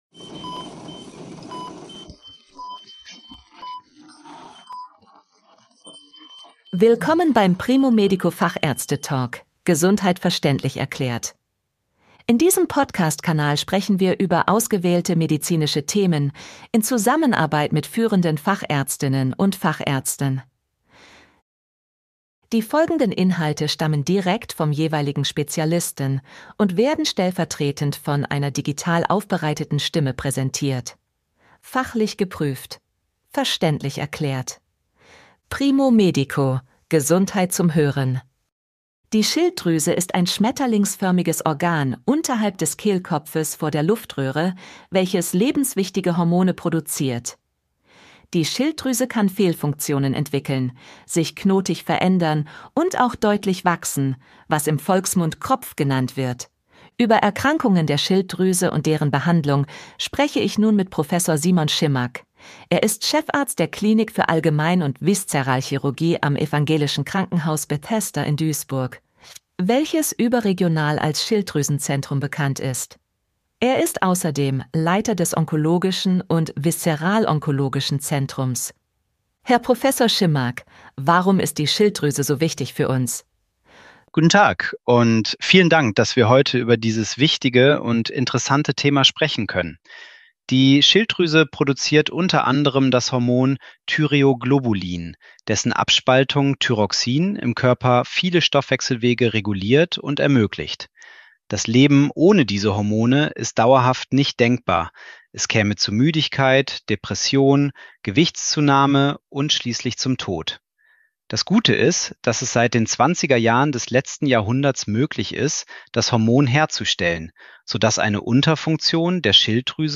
KI-gestützten Audiotechnologien produziert.